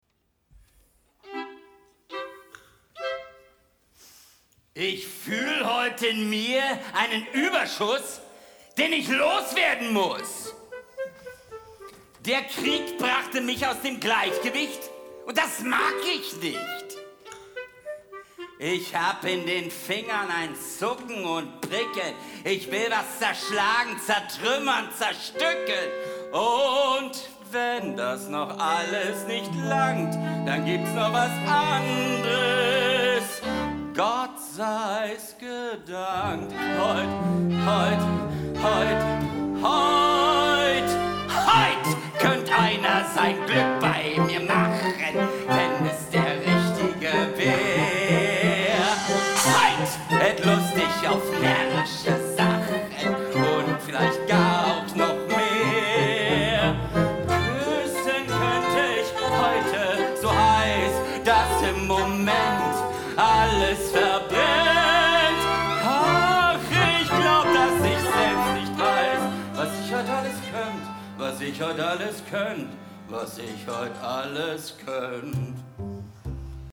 Eine Hybridoperette mit der Musicbanda Franui (2024)